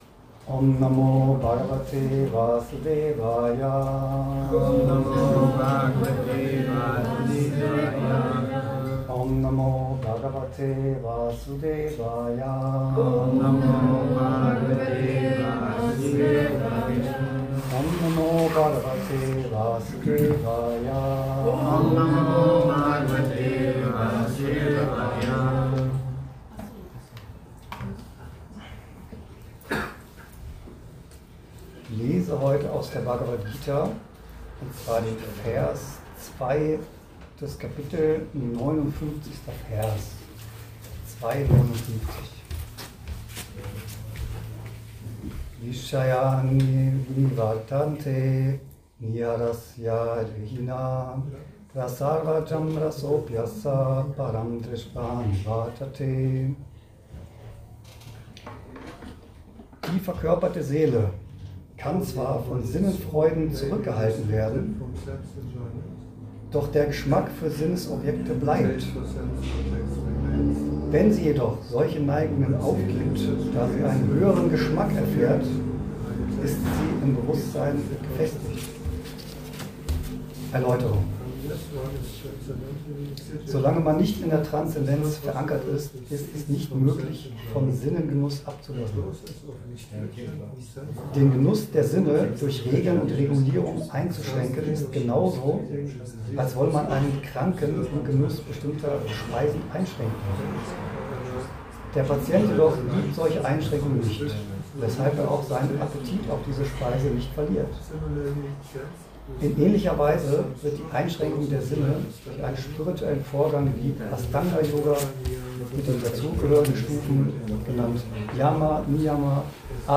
Was sagt Gott über Glück? – spiritueller Vortrag zu Bhagavad Gita 2.59
Vorträge im Bhakti Yoga Zentrum Hamburg